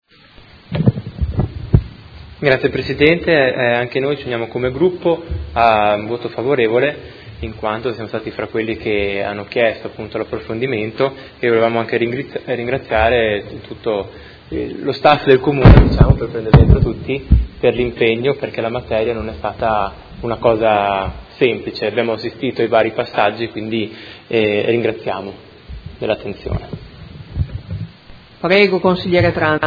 Dichiarazione di voto